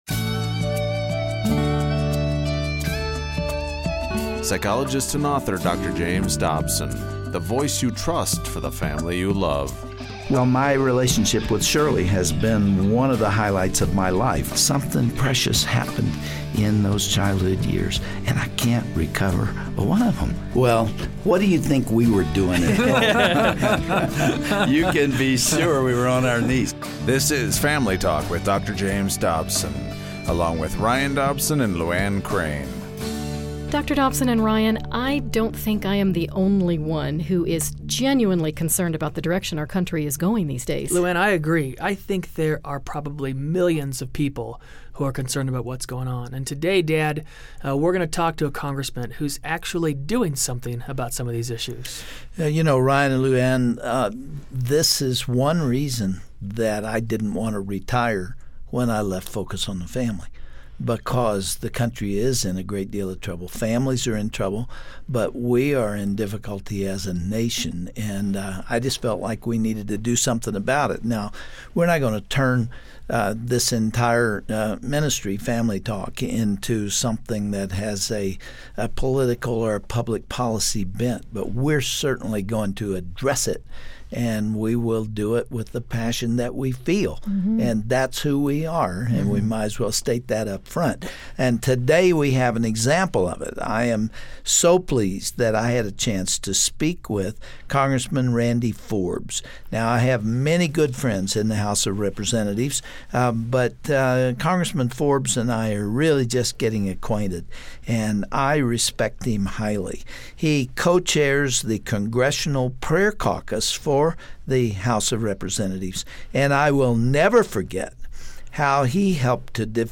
Summary: On today's program, Dr. Dobson sits down with Virginia Congressman Randy Forbes for a revealing interview about how forces in American society are sometimes surreptitiously removing all references to Christianity. Congressman Forbes describes the formation of the Congressional Prayer Caucus and the successes this group has had on the cultural battlefront.